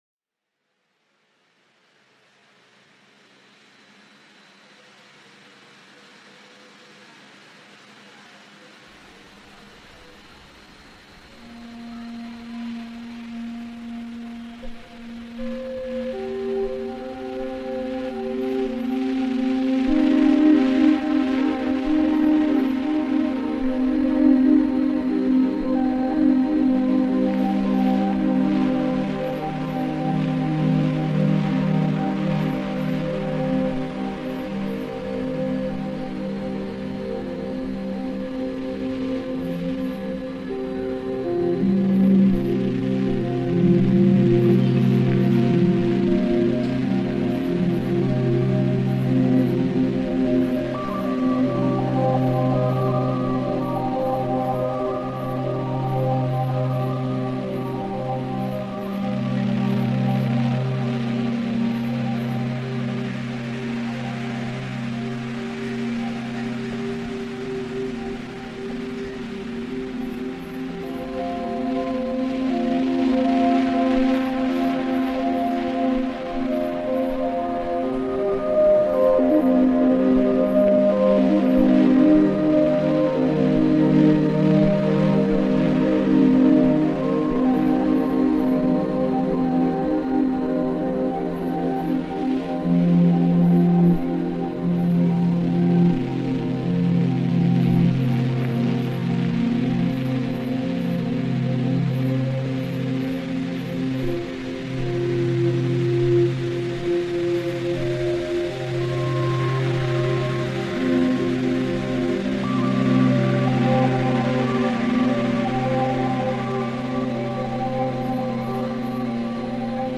ژانر: چاکرا